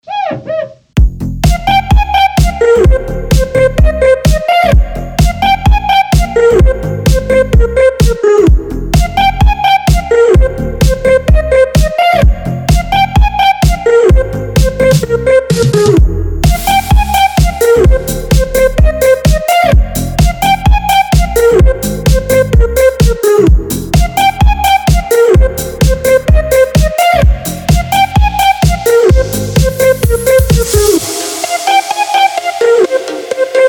• Качество: 192, Stereo
громкие
веселые
EDM
без слов
энергичные
Стиль: electronica